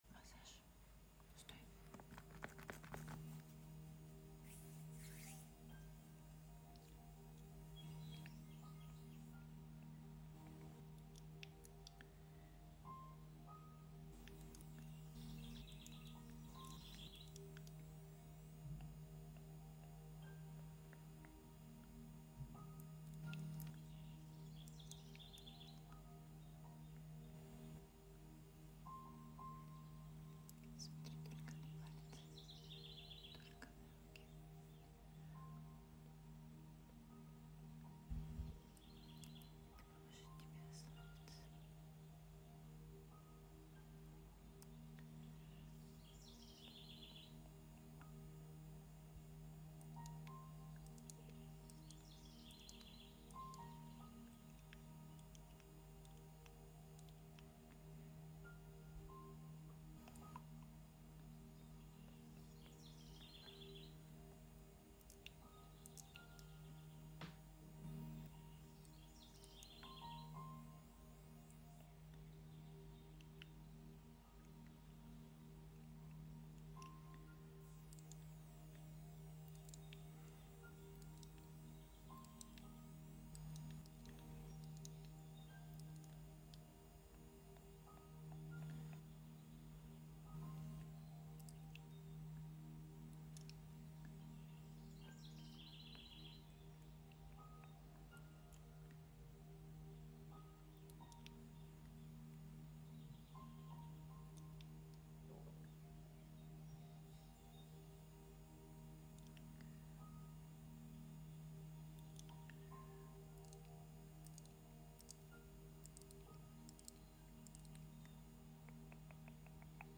ASMR massage before bed for sound effects free download
ASMR massage before bed for calm and relaxation.